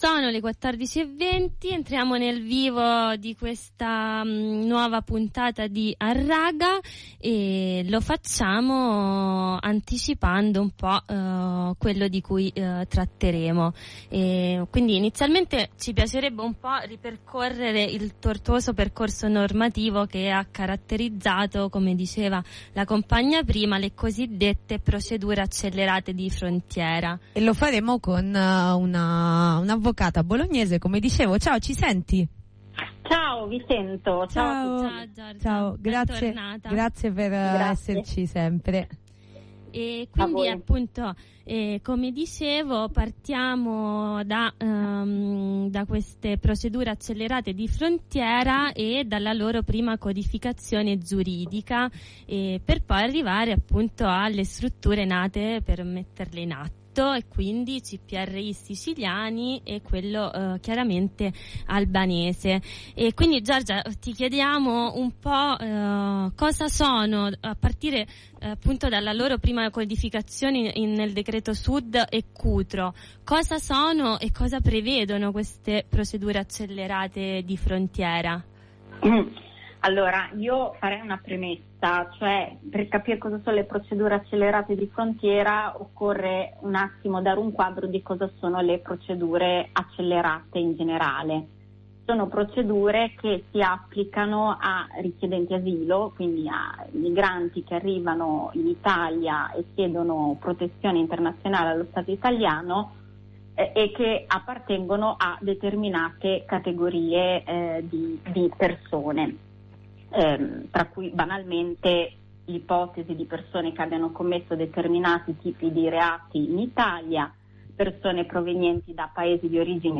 Nell’ultima puntata di Harraga – trasmissione che va in onda ogni venerdì sulle libere frequenze di Radio Blackout– abbiamo cercato di ricostruire il tortuoso percorso normativo che ha caratterizzato le cosiddette procedure accelerate di frontiera. Dalla recrudescenza della loro codificazione giuridica in Cutro fino ad arrivare all’Albania.